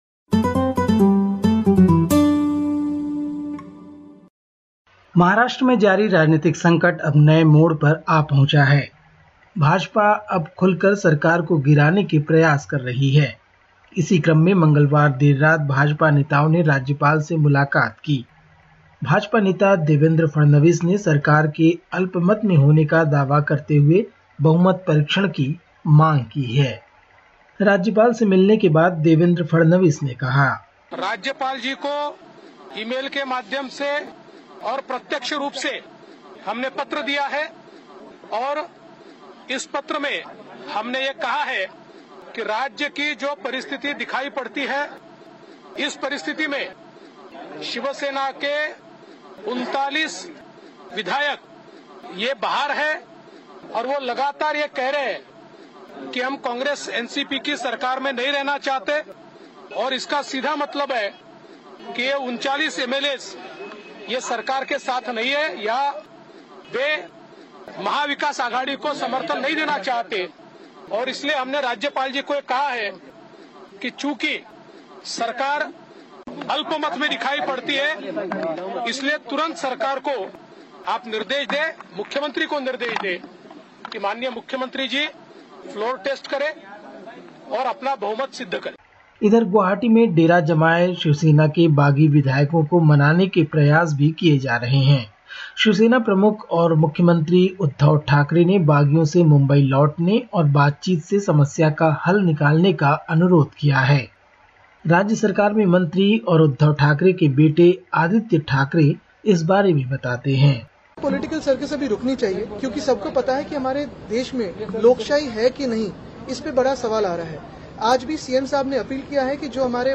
Listen to the latest SBS Hindi report from India. 29/06/2022